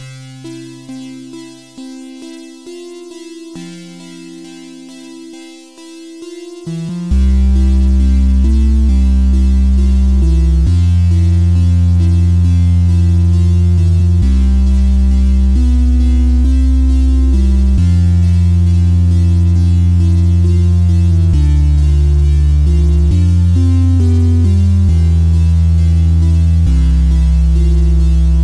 Testing out a new synth. Quite chilled!